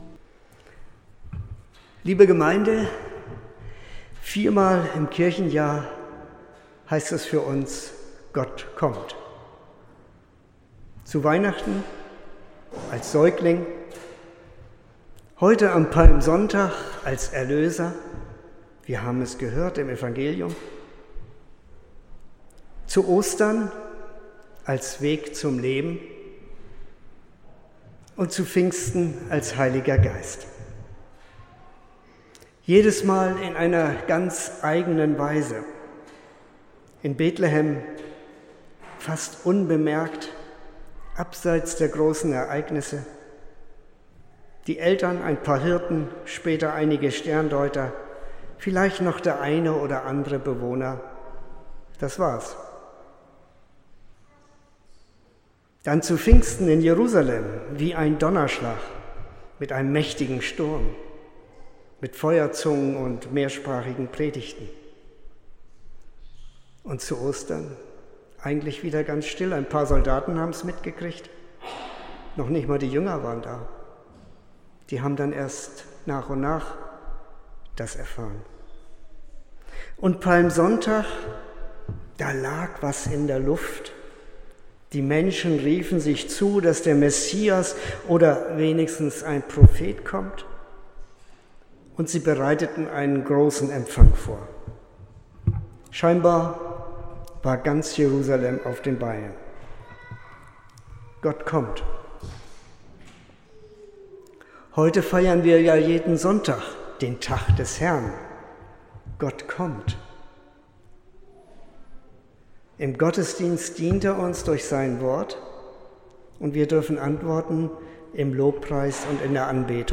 13.04.2025 – Gottesdienst
Predigt und Aufzeichnungen